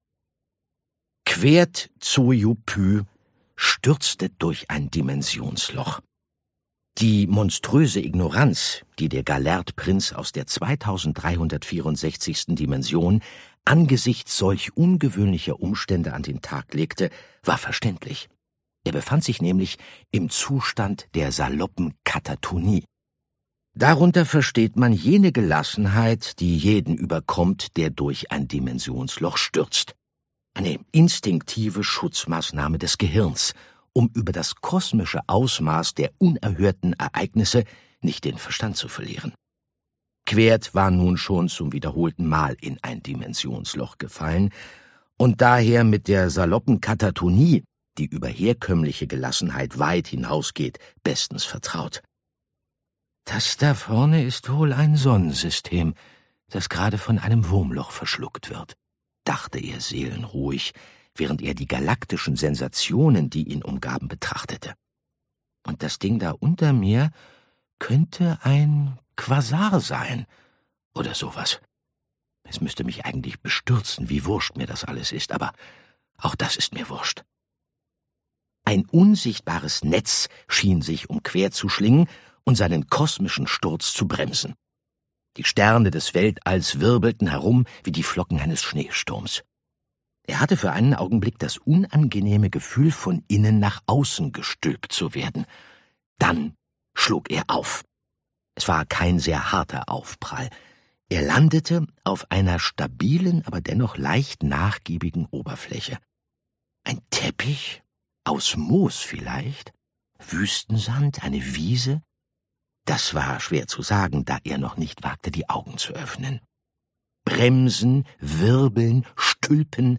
Andreas Fröhlich (Sprecher)
2025 | Ungekürzte Lesung